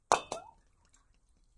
描述：Fiddling with small glass jars.
标签： bottle bottles glass
声道立体声